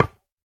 Minecraft Version Minecraft Version snapshot Latest Release | Latest Snapshot snapshot / assets / minecraft / sounds / block / bone_block / break5.ogg Compare With Compare With Latest Release | Latest Snapshot
break5.ogg